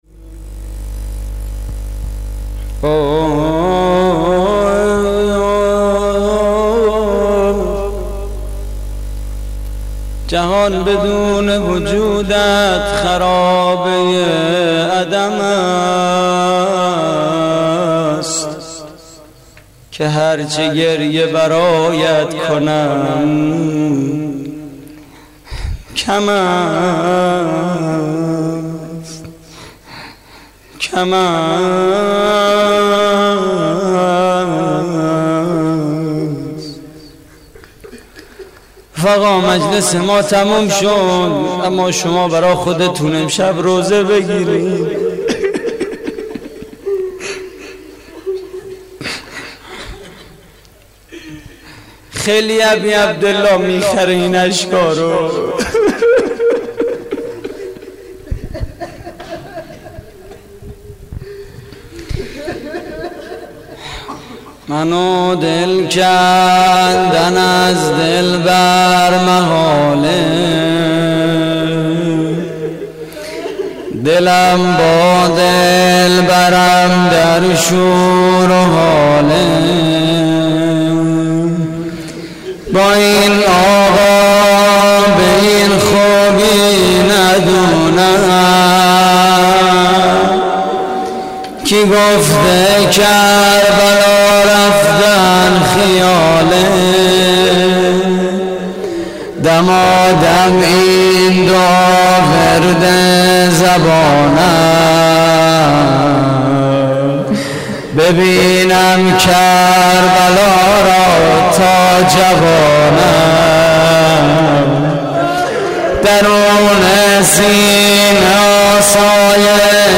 مراسم عزاداری شب سوم محرم 1432 / هیئت کریم آل طاها (ع) – شهرری؛ 7 آذر 1390